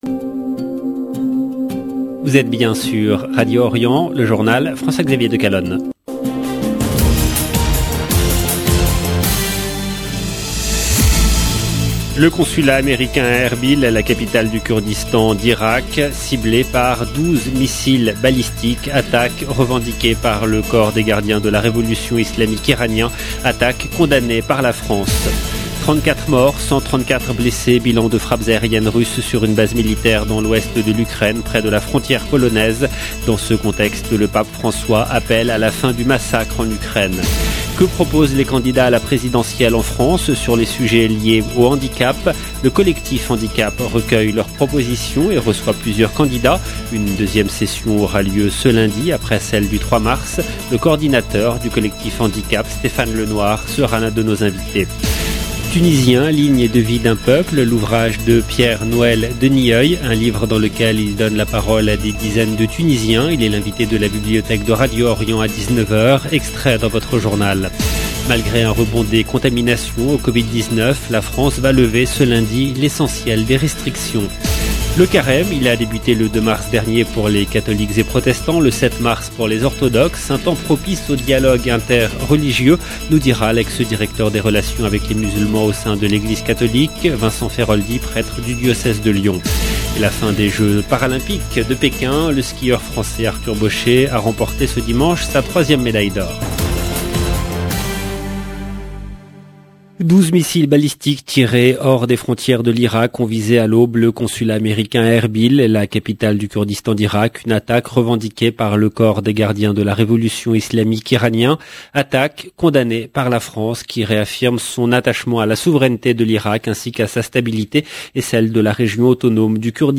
EDITION DU JOURNAL DU SOIR EN LANGUE FRANCAISE DU 13/3/2022